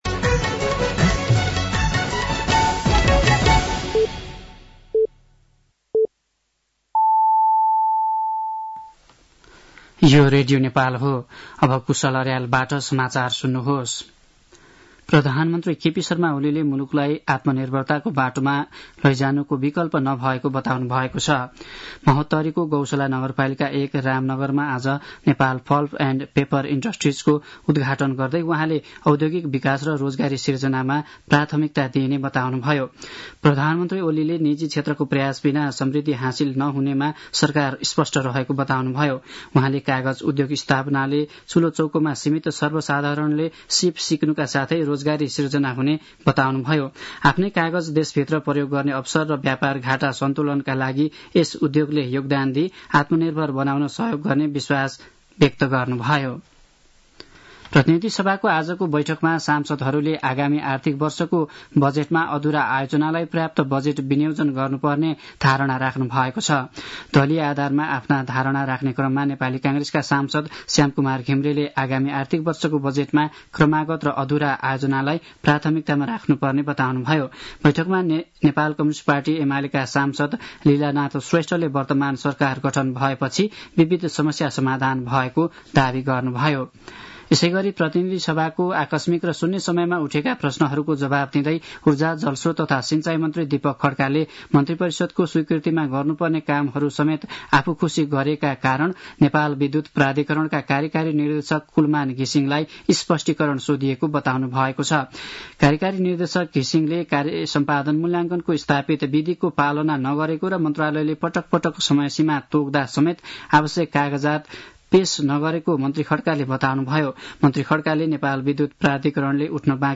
साँझ ५ बजेको नेपाली समाचार : २३ फागुन , २०८१
5-pm-news-3.mp3